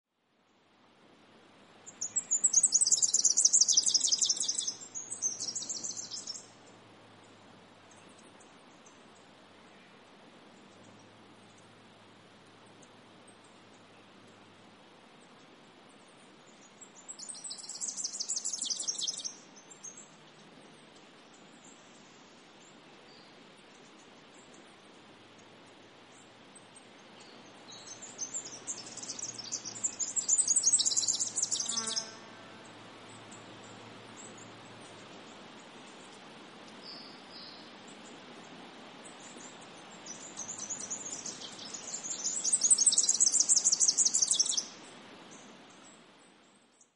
Superb Fairywren - Malurus cyaneus
Voice: brisk, accelerating trilling, quiet contact calls.
Call 1: trills.
Superb Fairywren.mp3